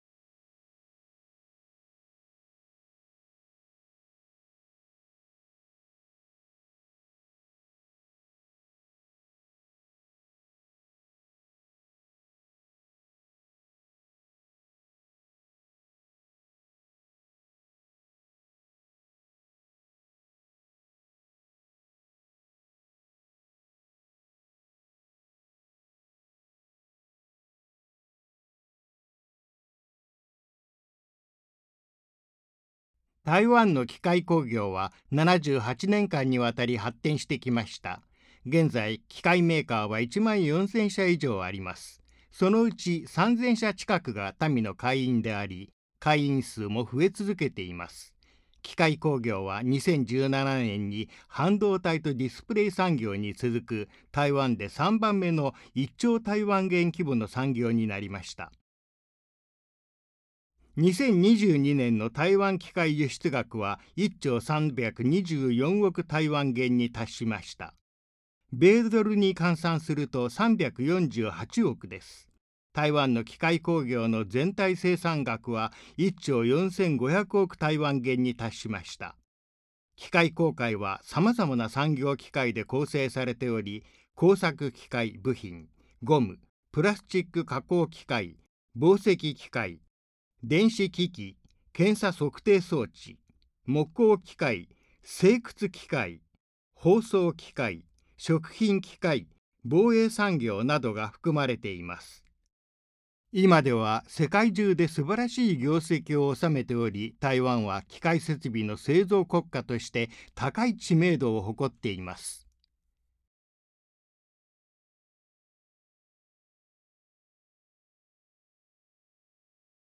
外語配音 日語配音 男性配音員
• 日本聲優 / 日本配音員
• 動畫遊戲配音
• 聲線多變配音員